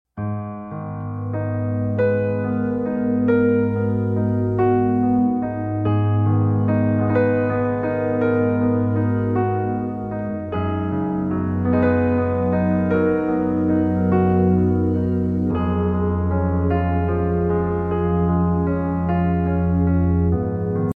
In a landscape of destruction and ruins, one robot plays a haunting melody on a piano, while another creates a beautiful painting amidst the chaos.